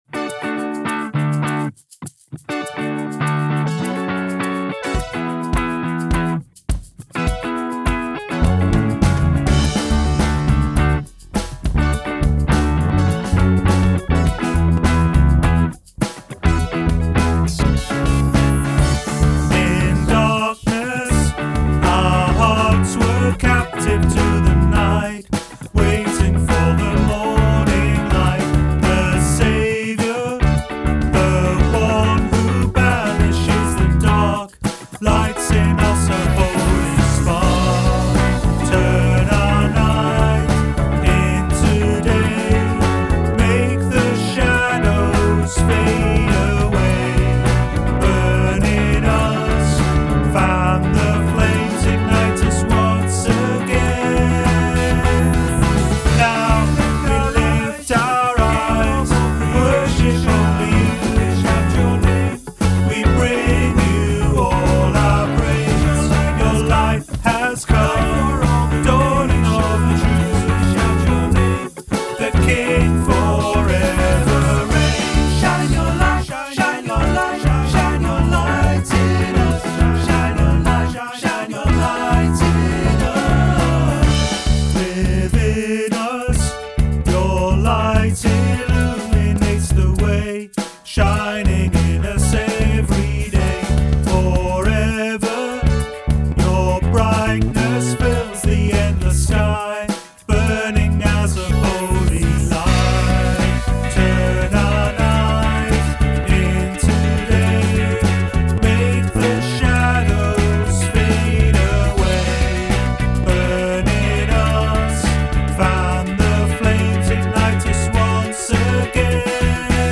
• Praise